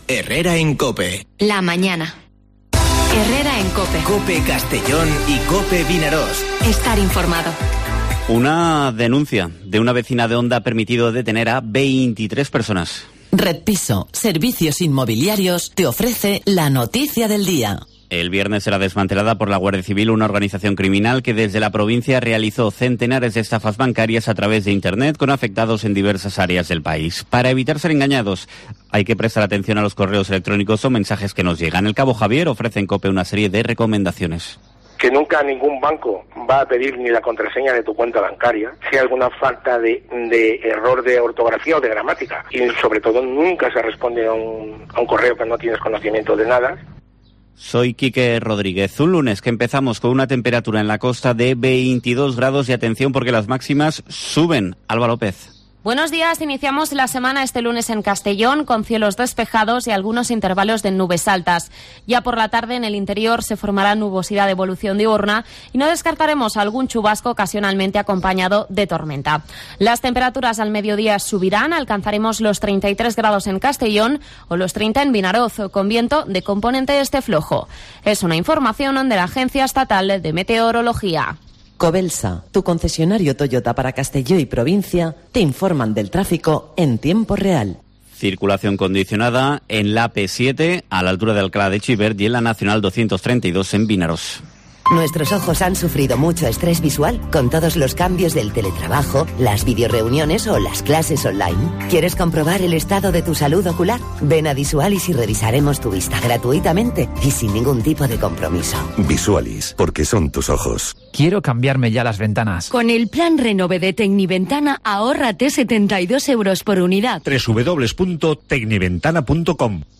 Informativo Herrera en COPE en la provincia de Castellón (14/06/2021)